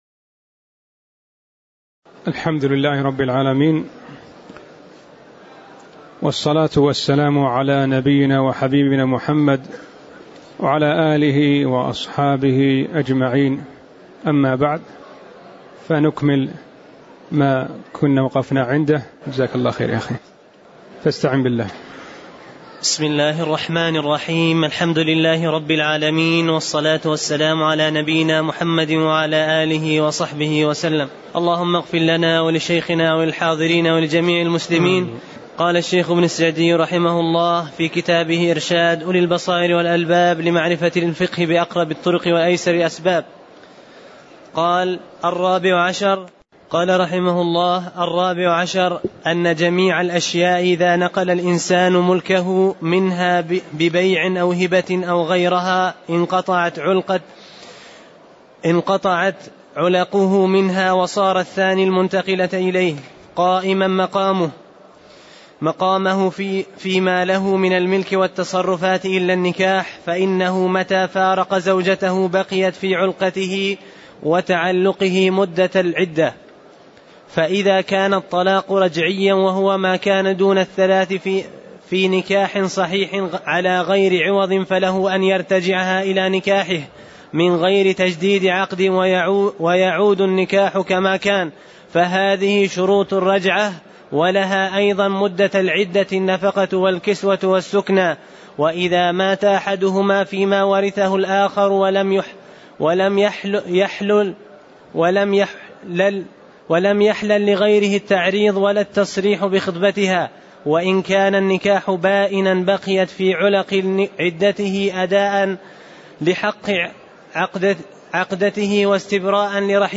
تاريخ النشر ٢١ شوال ١٤٣٨ هـ المكان: المسجد النبوي الشيخ